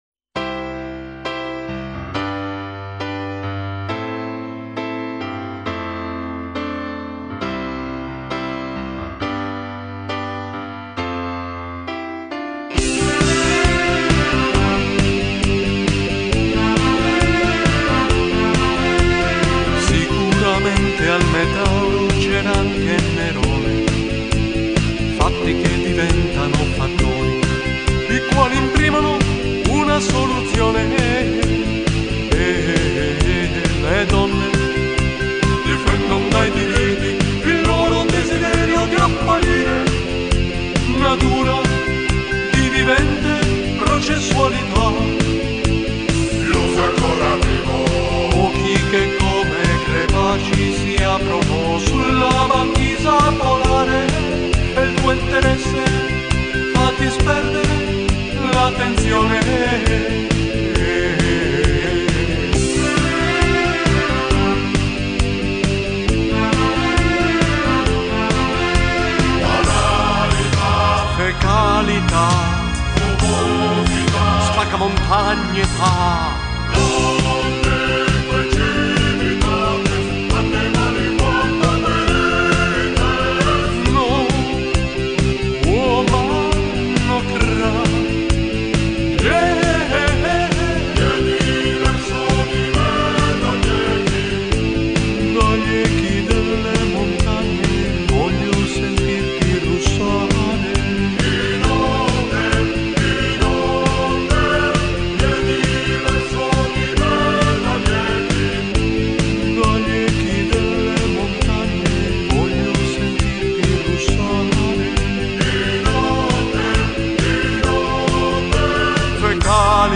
Introduzione pianoforte piccolo frammento/citazione
coro
chitarra